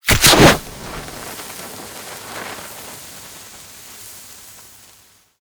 Incendiary_Near_03.ogg